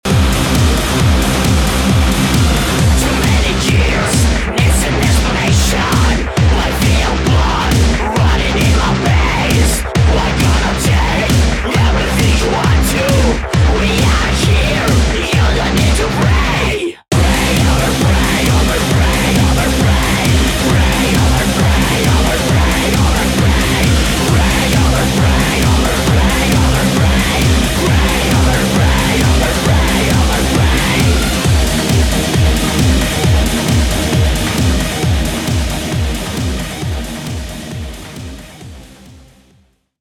Satanischer Death Industrial aus Mexico.